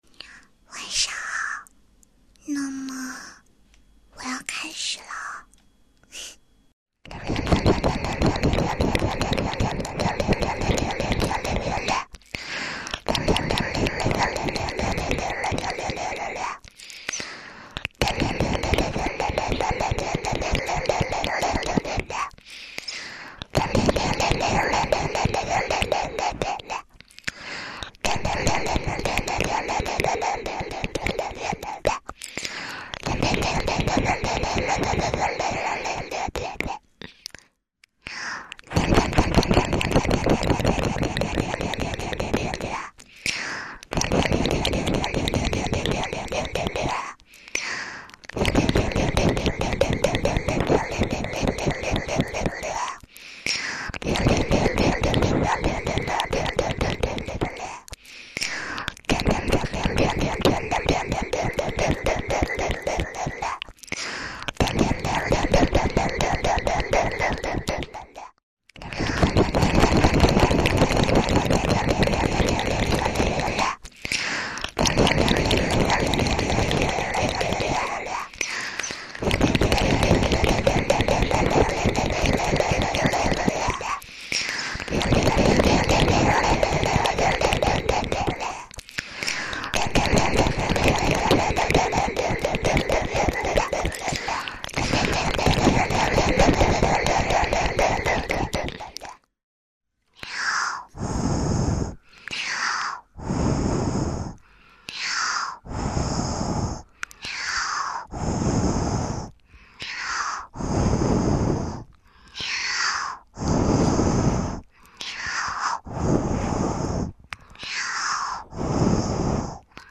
双重禁忌。有点刺激 亲吻音muamua~.mp3